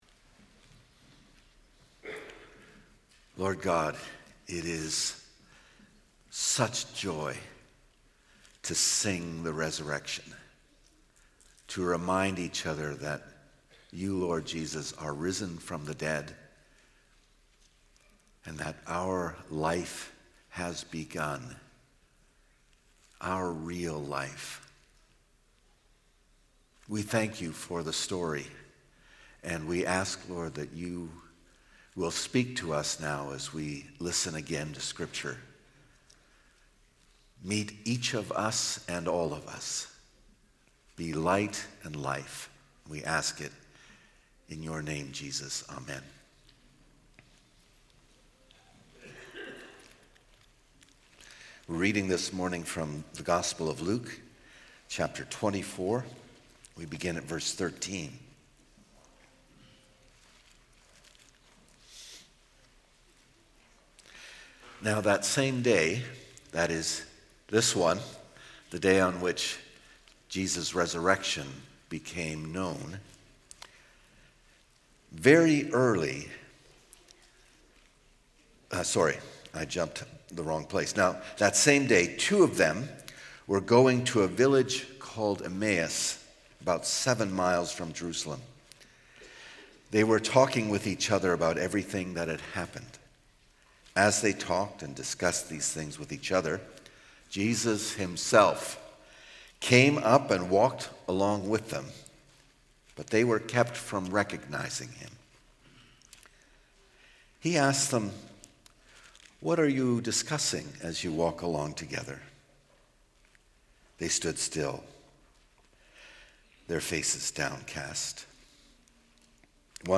Sermons | Community Christian Reformed Church
Easter Sunday